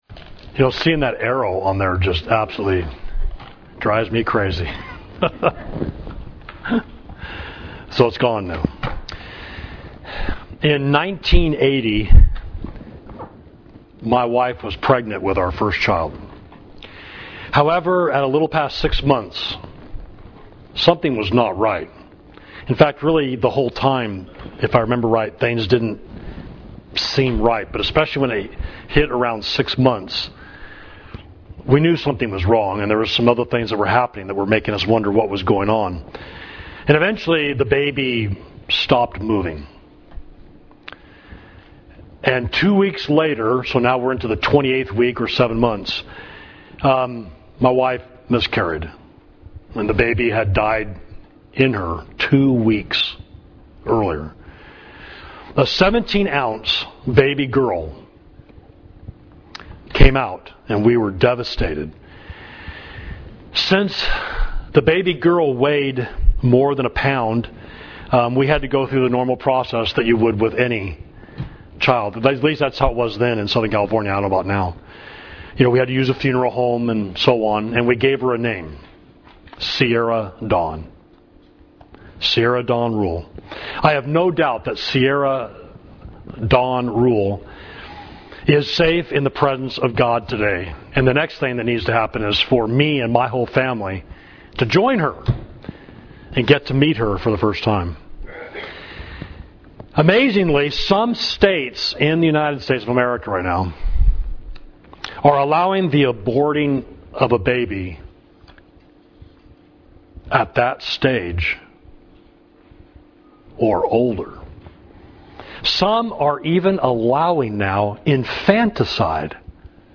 Sermon
Savage Street, Grants Pass, Oregon